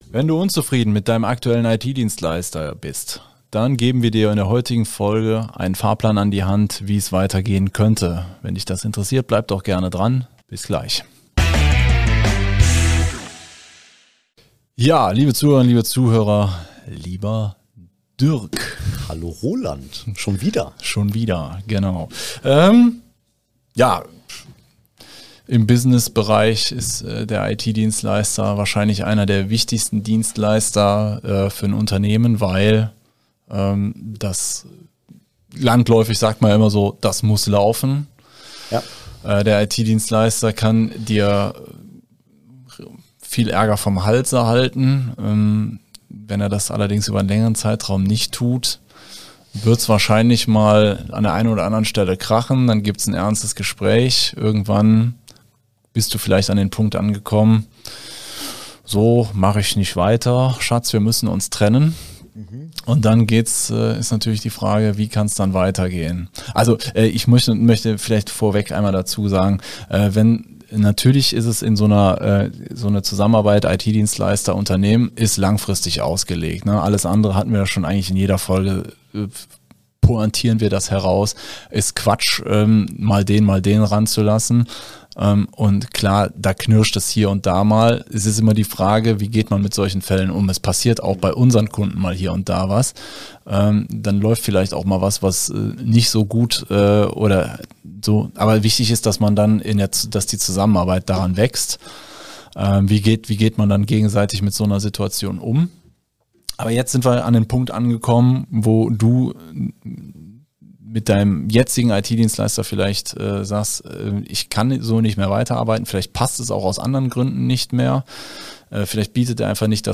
Um dir dieses IT-Wissen zu vermitteln, greifen wir auf eine Doppel-Besetzung zurück: Den Techniker